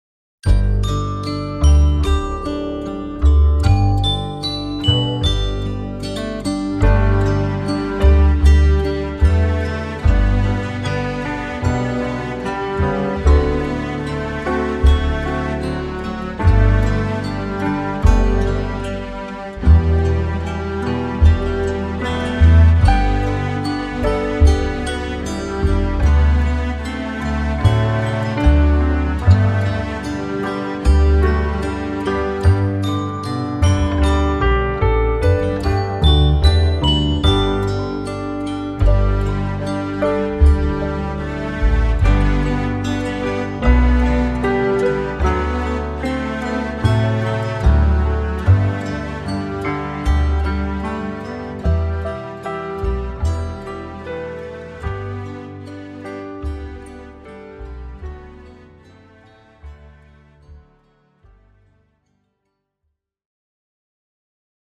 Latviešu tautas dziesma flautai Play-along.
Spied šeit, lai paklausītos Demo ar melodiju